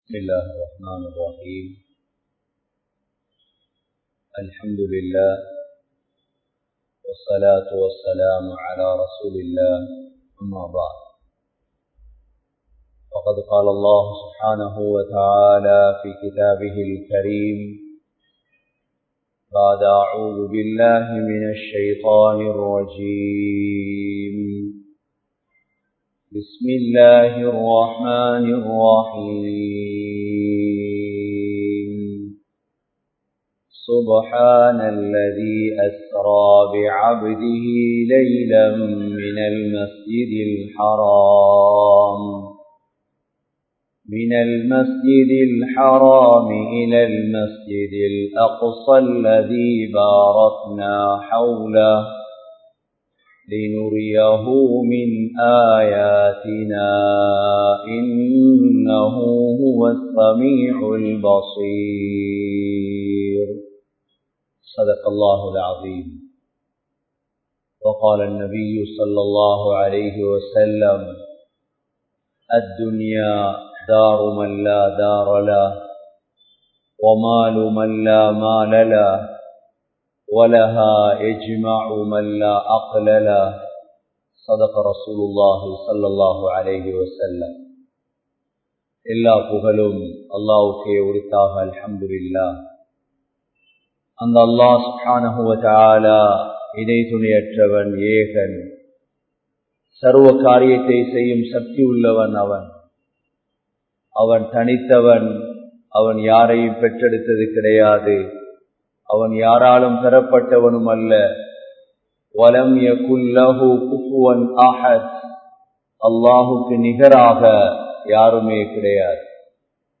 Tholuhai Illaathavanin Nilai (தொழுகை இல்லாதவனின் நிலை) | Audio Bayans | All Ceylon Muslim Youth Community | Addalaichenai
Grand Jumua Masjith